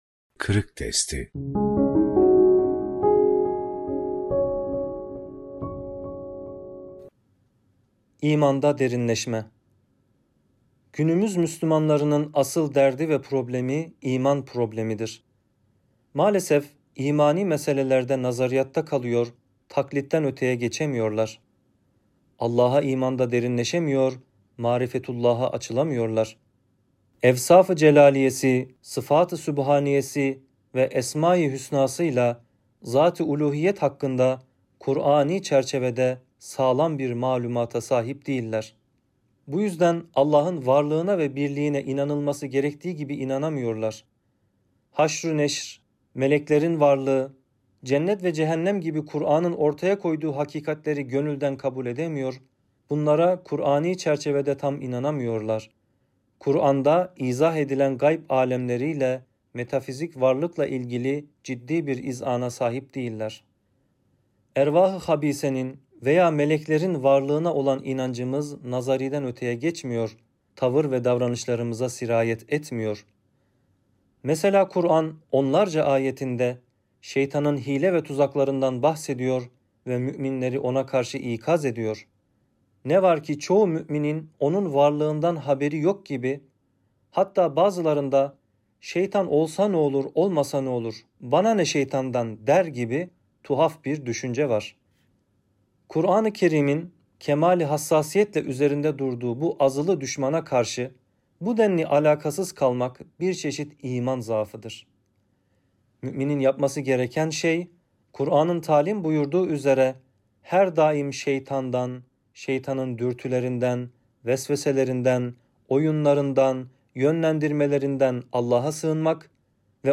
İmanda Derinleşme - Fethullah Gülen Hocaefendi'nin Sohbetleri